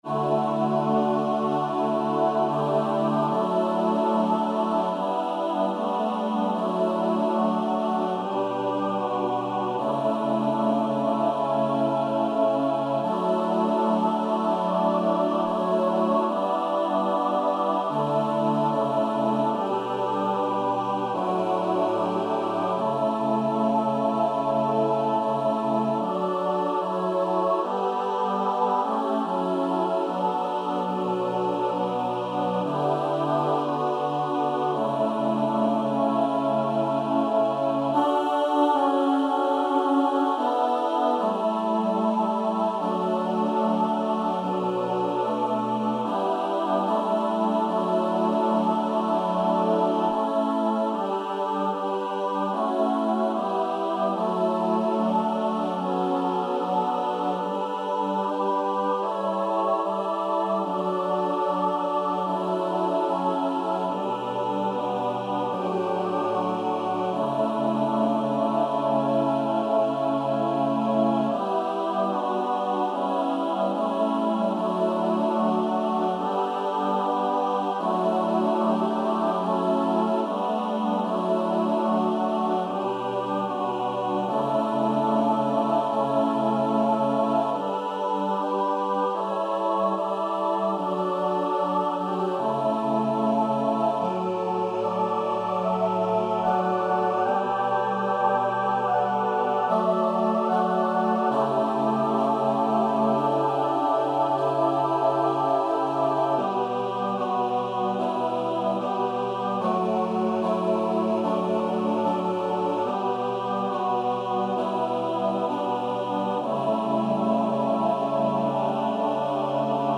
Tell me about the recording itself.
Performance